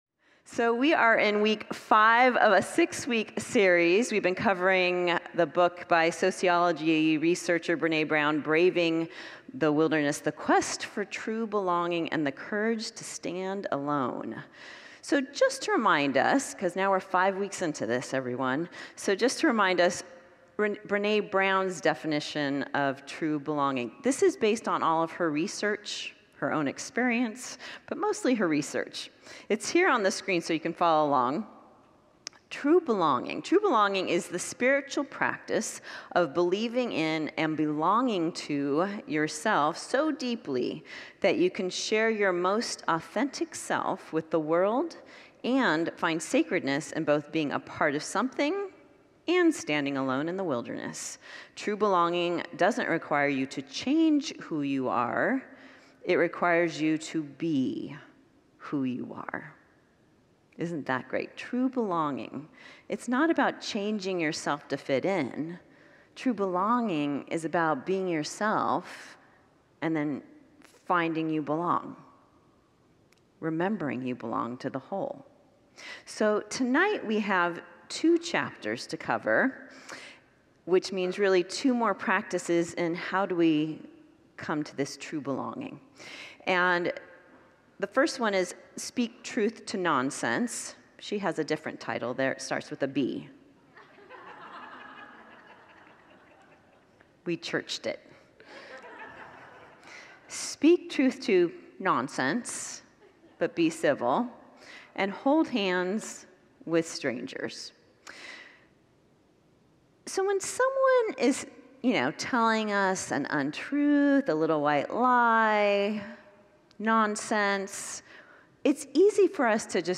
Series: Wednesday Evening Worship